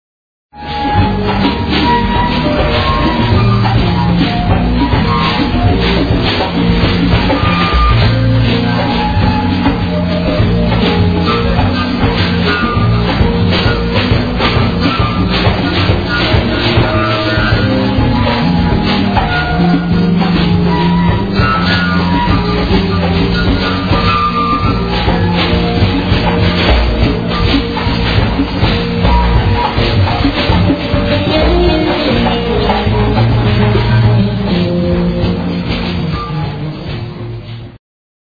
For those, who are not "in the picture" - Fujara is Slovak traditional wood instrument (a thing looking like a "big pipe"), with characteristic, unreplaceable sound.
fujara
guitar
bass
drums
percussion) - live from Antonius workshop